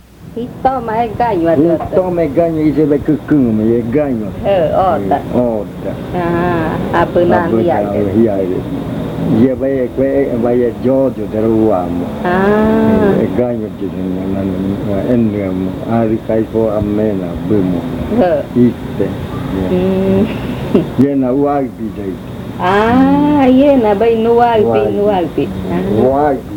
Leticia, Amazonas
Explicación de la adivinanza. Hace una comparación del sol con la cola de la ardilla, que es reluciente como el sol.
Explanation of the riddle.